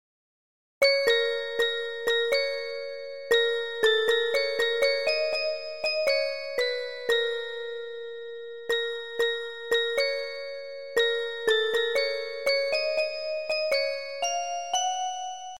Relaxing Music